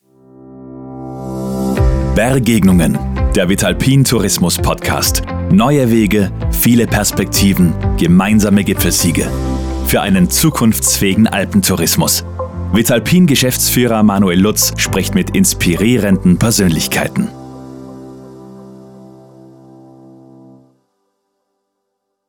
Werbesprecher
Tempo, Melodie, Intensität bestimmen den Klang.
Meine Stimme
Podcast_Vitalpin_INTRO.wav